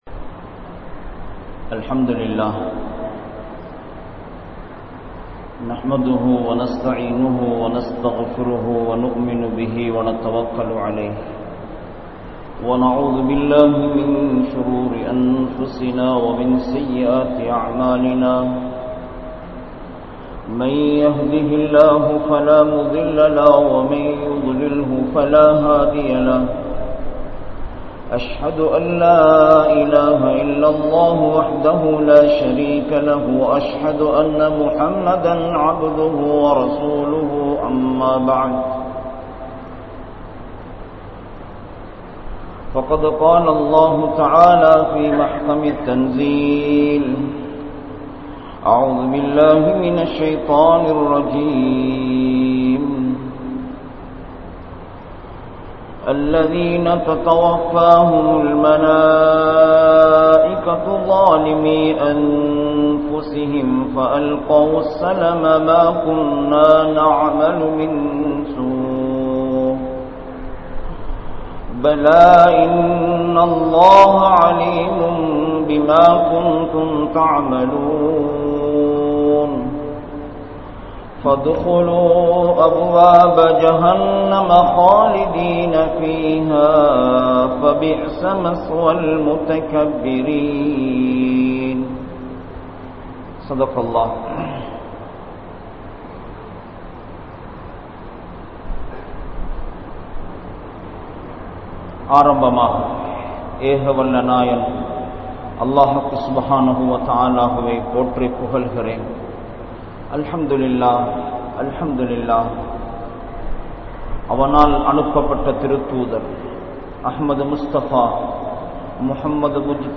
Uyir Pirium Nimidam | Audio Bayans | All Ceylon Muslim Youth Community | Addalaichenai